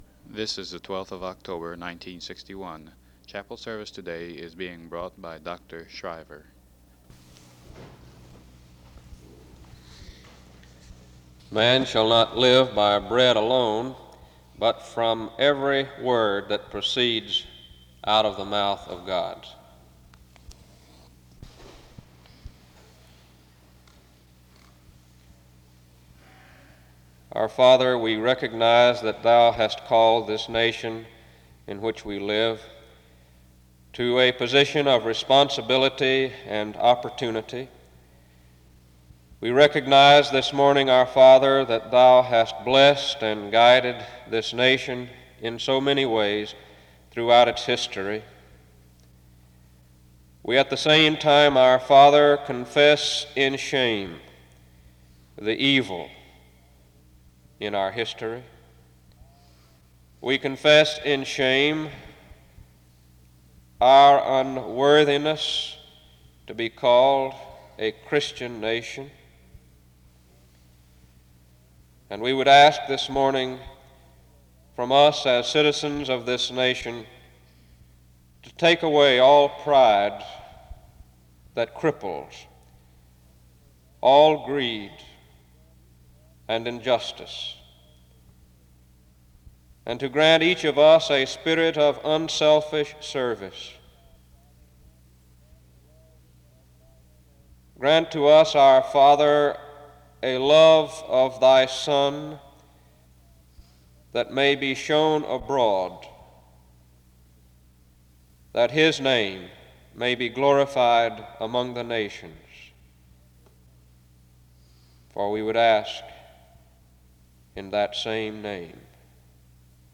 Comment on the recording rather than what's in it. Home SEBTS Chapel